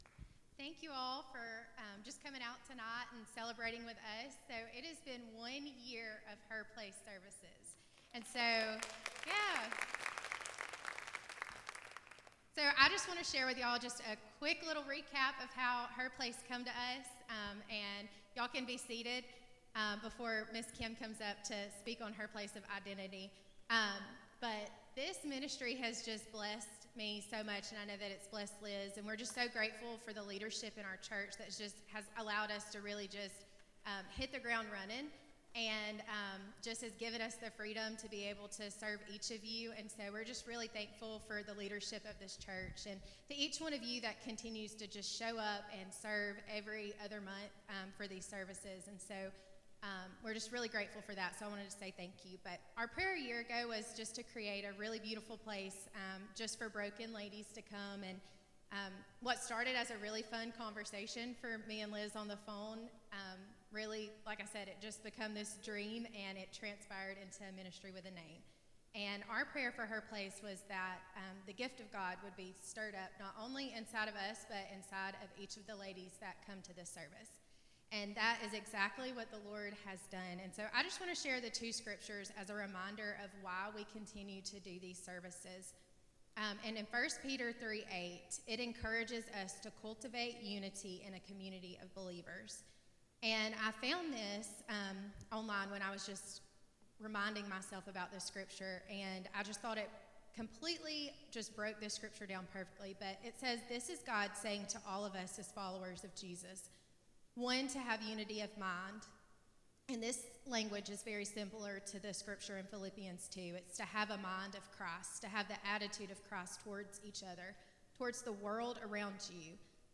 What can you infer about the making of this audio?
Listen in ladies as we celebrate one year of Her Place services together.